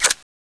awp_clipout.wav